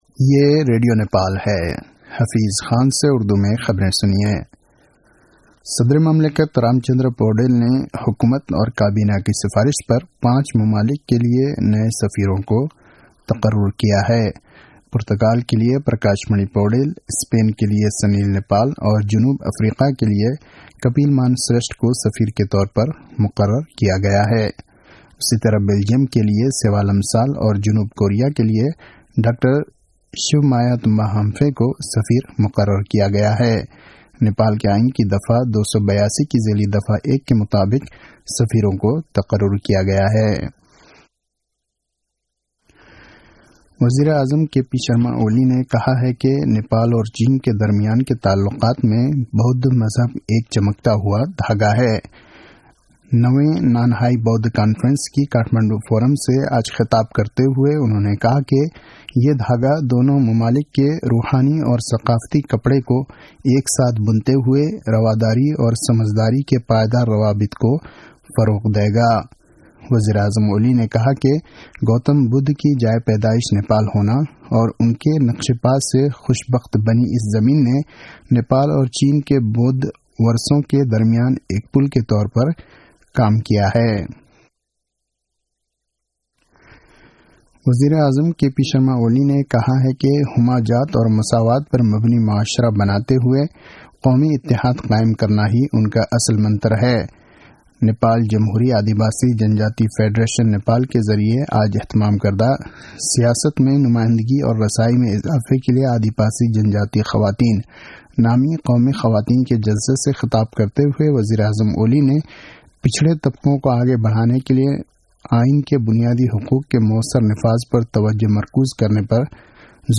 उर्दु भाषामा समाचार : २९ मंसिर , २०८१